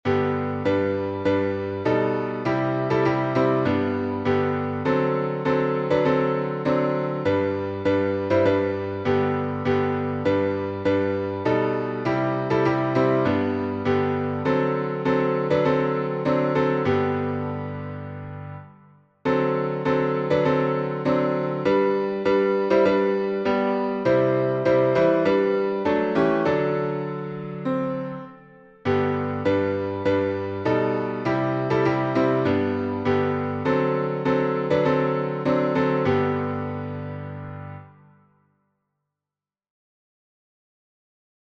Tell It to Jesus — G major.